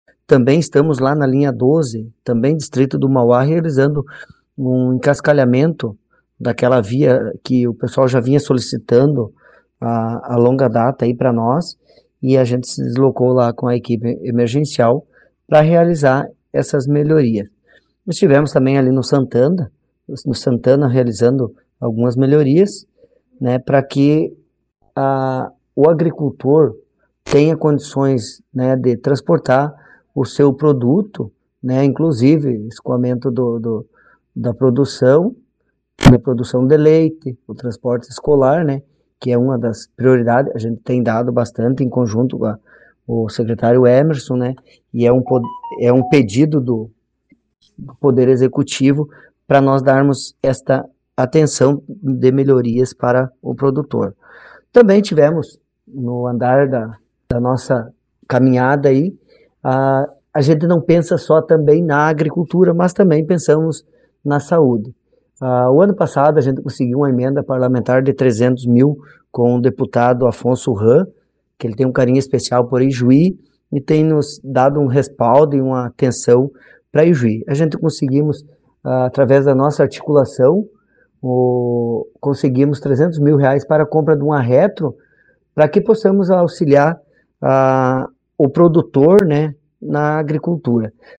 O secretário adjunto de Desenvolvimento Rural de Ijuí, Valdenei Wagner dos Santos – o Popeye – confirmou ao jornalismo da Repórter que a pasta tem intensificado as ações de melhorias nas estradas do interior do município.